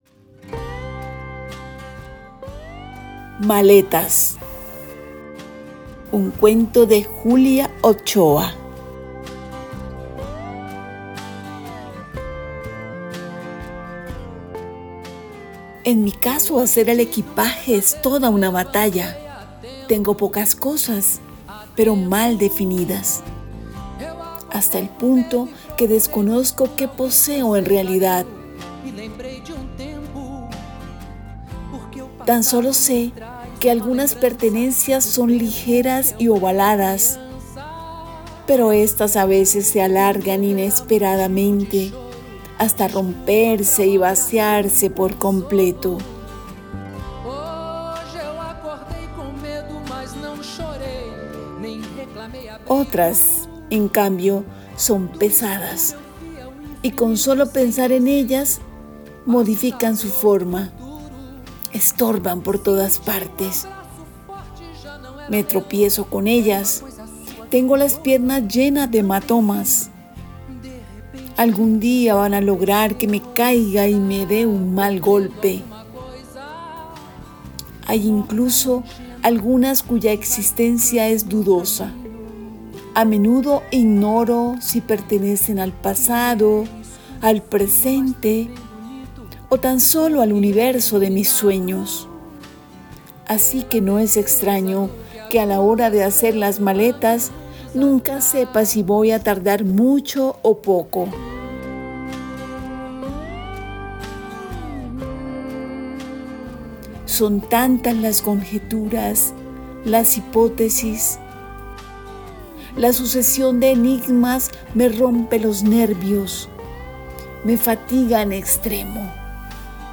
Tema musical: “Poema” del artista brasilero Ney Matogrosso, del álbum Olhos de Farol.